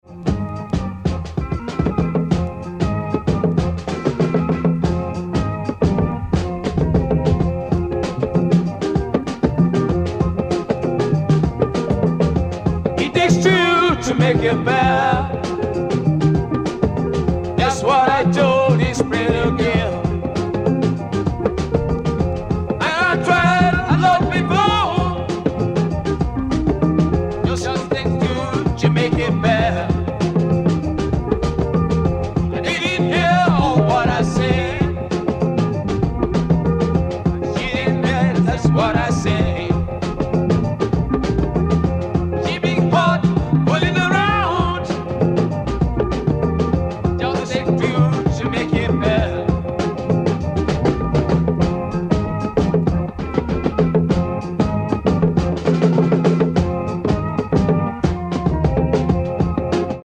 This time Psych-Soul-Funk gets a serious rinse, AWESUM!!